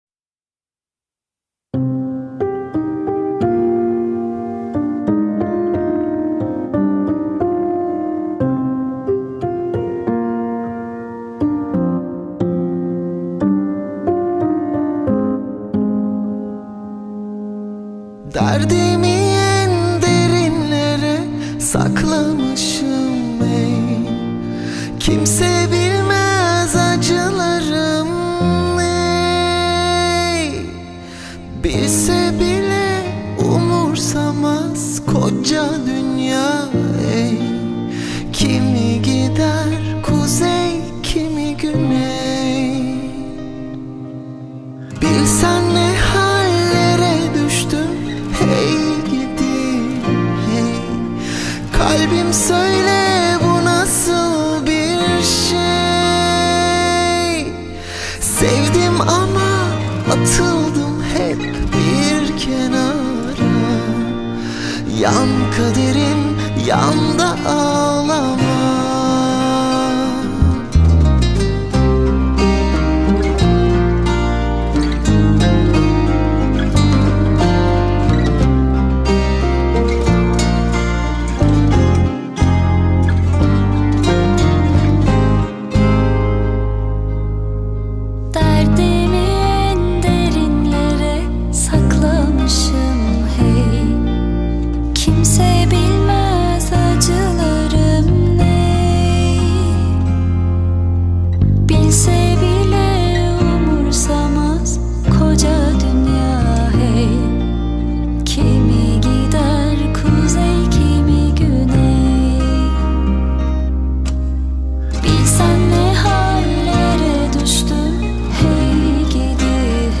dizi müziği, duygusal hüzünlü rahatlatıcı şarkı.